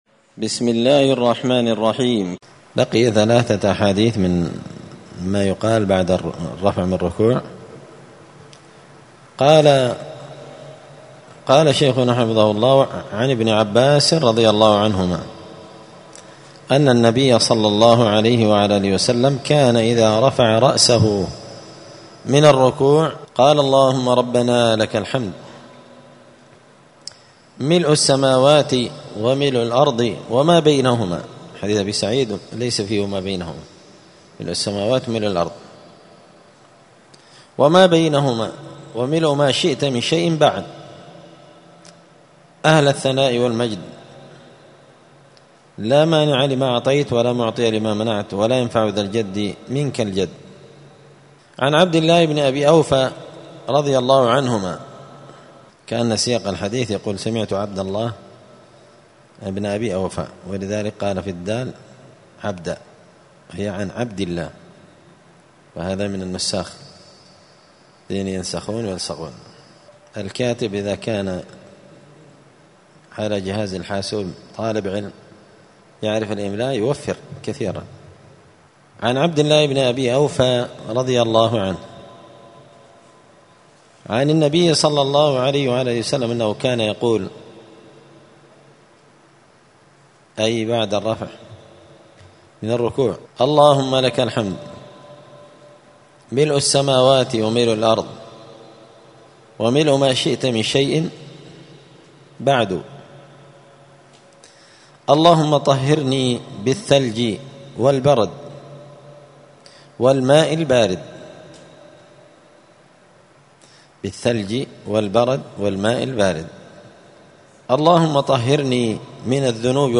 *{الدرس الثلاثون (30) أذكار الصلاة تابع للذكر حال الرفع من الركوع وبعد الاعتدال منه}*
دار الحديث السلفية بمسجد الفرقان بقشن المهرة اليمن